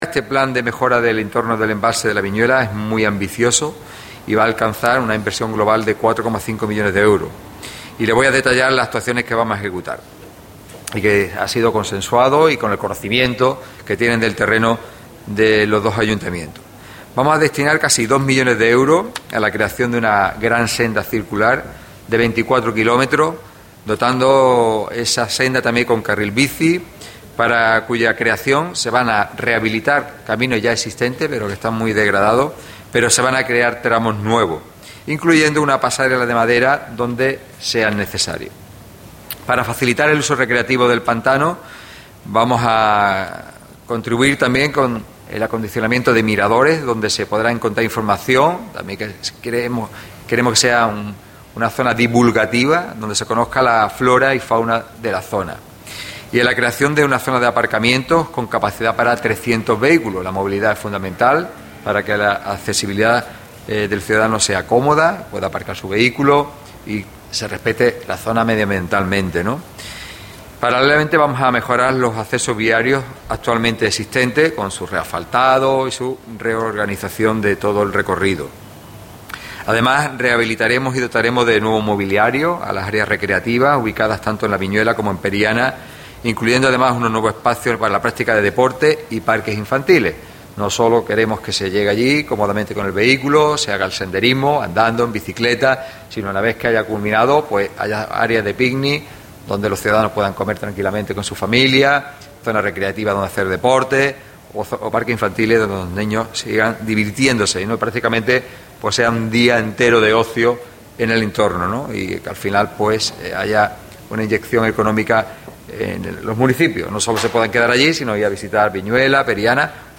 francisco-salado-detalla-actuaciones-del-plan-del-pantano-de-la-vinuela.mp3